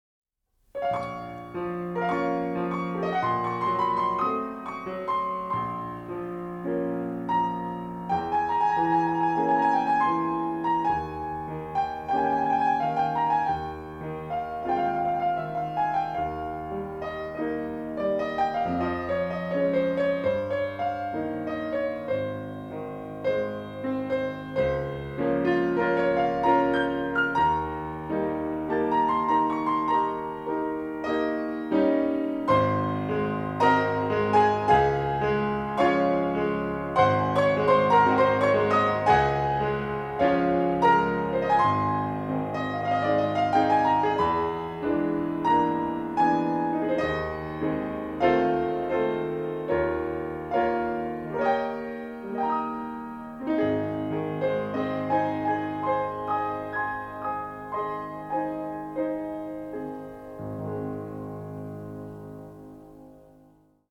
synthesizers morphed with traditional orchestral instruments